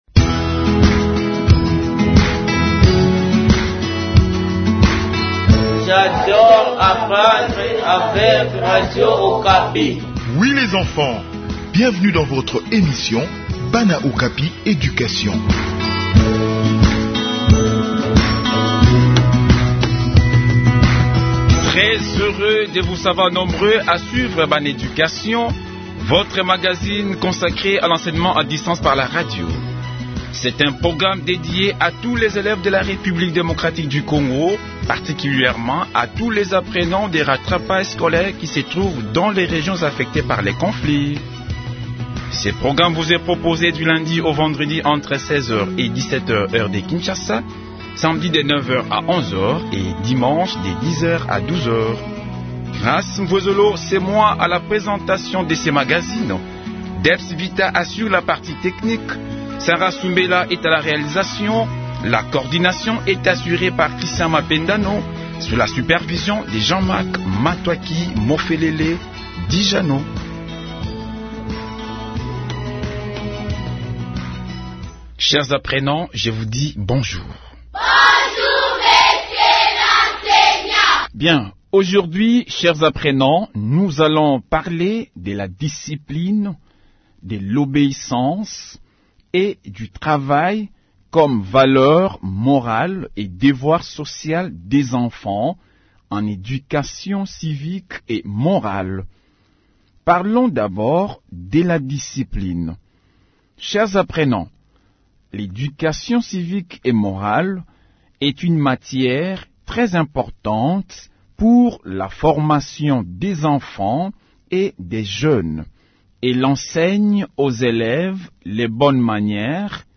Enseignement à distance : leçon sur la discipline